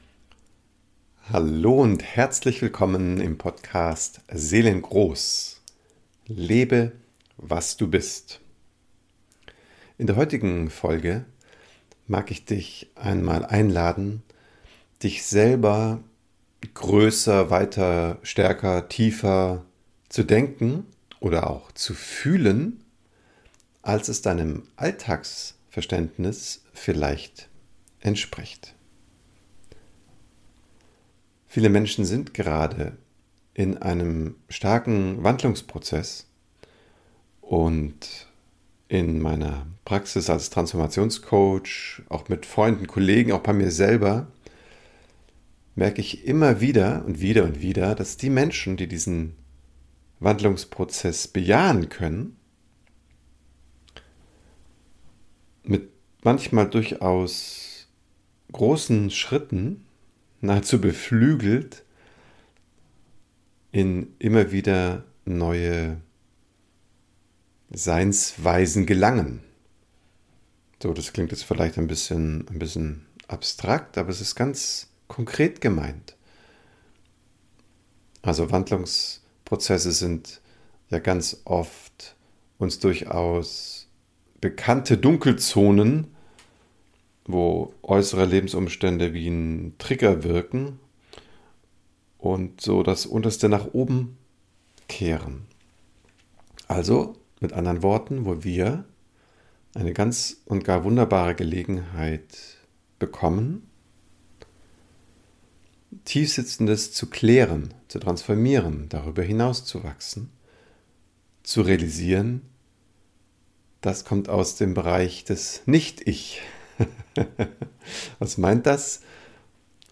Die heutige Episode enthält am Ende eine etwa 15 minütige Meditation, die dich dazu einlädt deine Seelengröße zu leben.